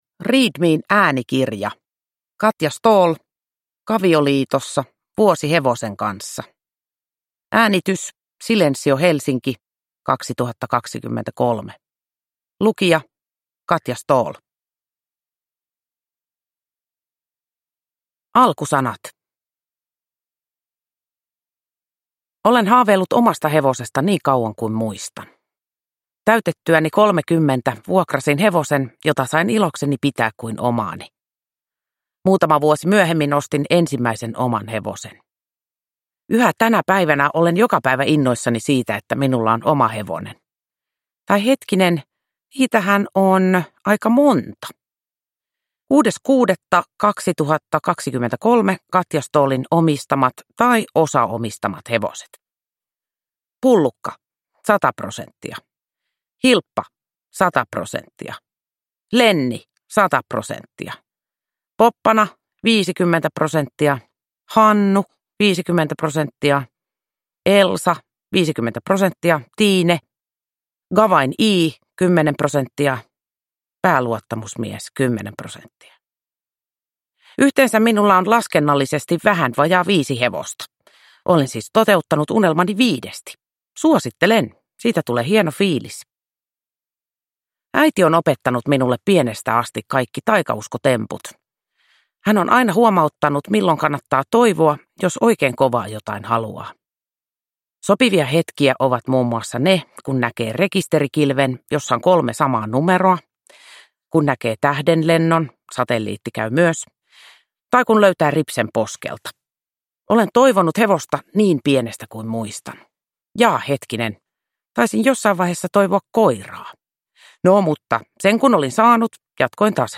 Kavioliitossa (ljudbok) av Katja Ståhl